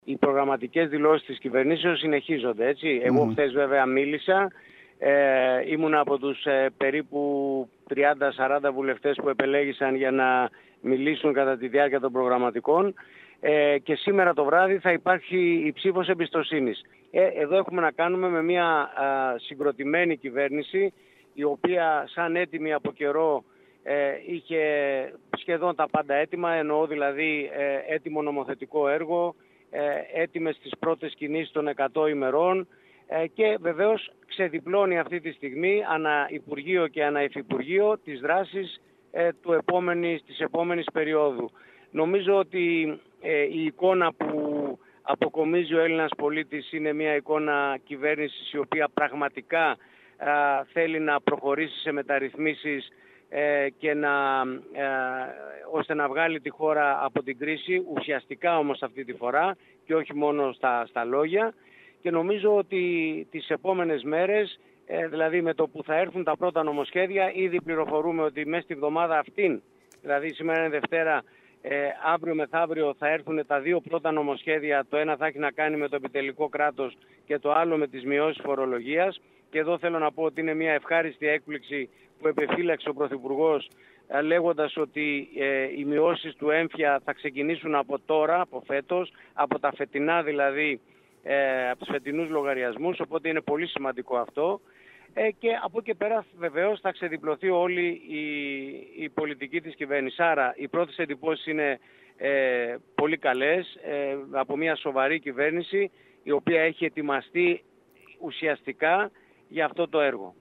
Τις προγραμματικές δηλώσεις της κυβέρνησης σχολίασαν μιλώντας στην ΕΡΤ Κέρκυρας, οι βουλευτές του νησιού Στ. Γκίκας, Αλ. Αυλωνίτης και Δ. Μπιάγκης.